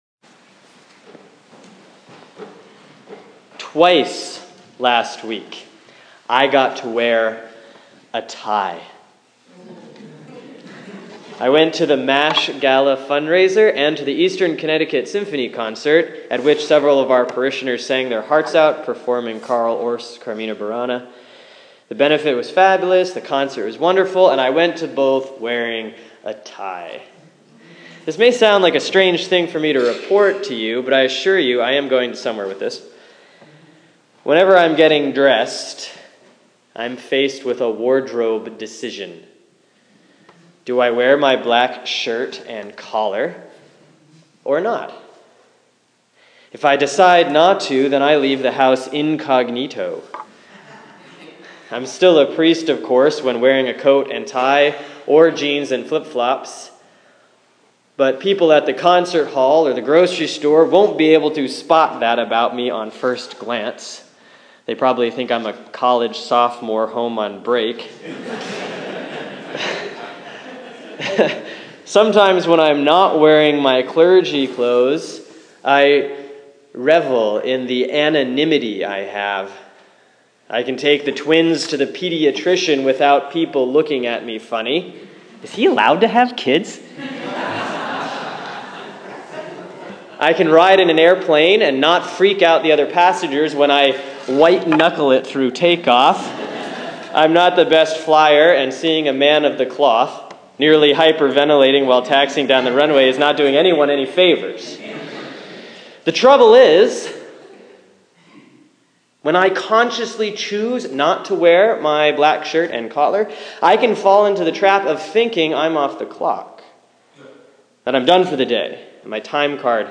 Sermon for Sunday, May 3, 2015 || Easter 5B || Acts 8:26-40